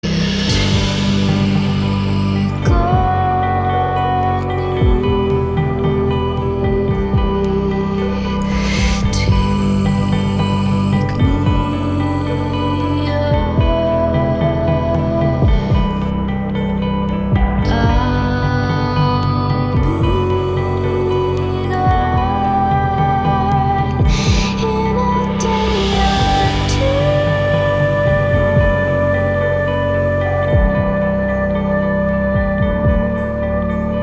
• Качество: 320, Stereo
красивые
спокойные
нежные